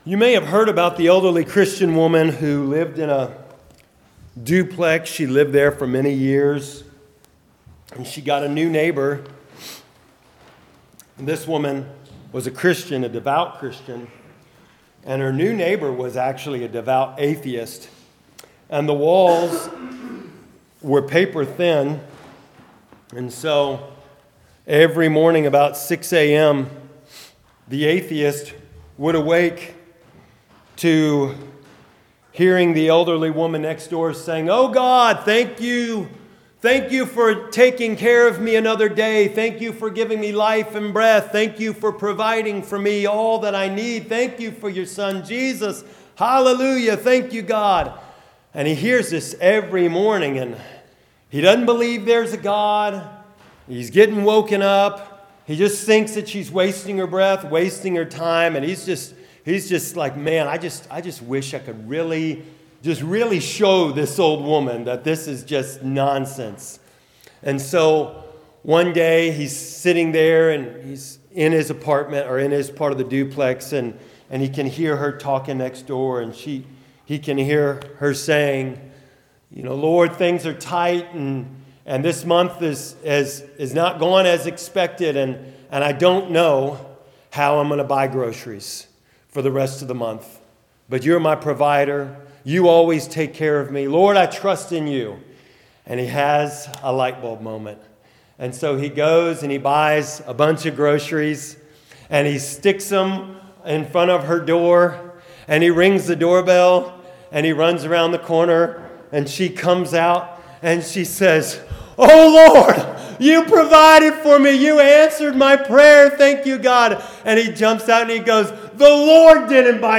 Sermons | First Baptist Church